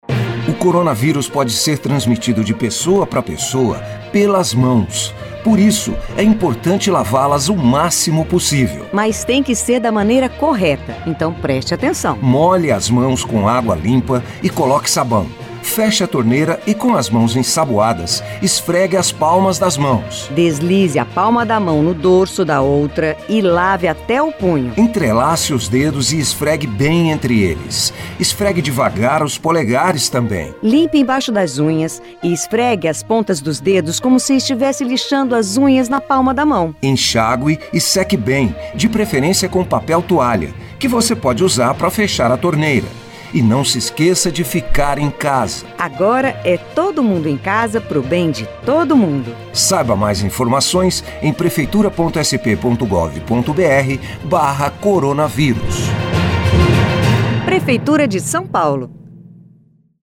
02. Rádio
Spot 60” | Prevenção - 02